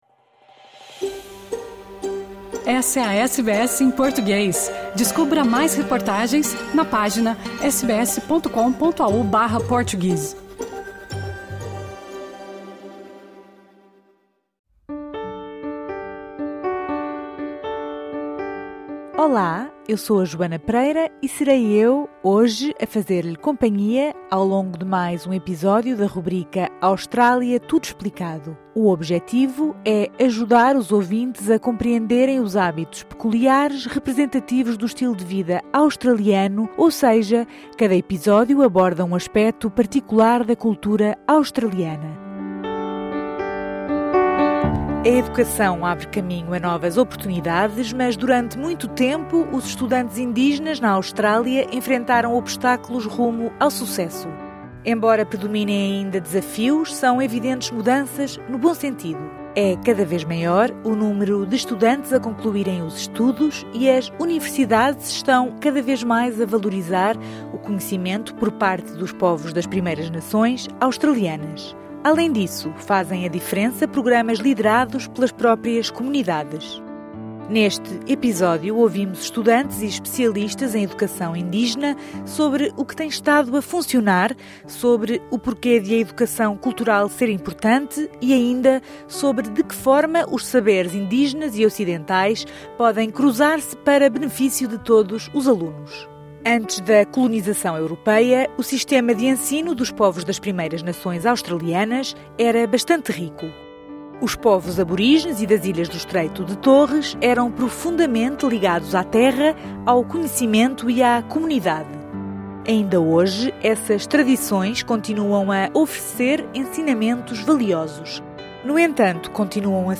Neste episódio, ouvimos estudantes e especialistas em educação Indígena sobre o que tem estado a funcionar, sobre o porquê de a educação cultural ser importante e, ainda, sobre de que forma os saberes indígenas e ocidentais podem cruzar-se para benefício de todos os alunos.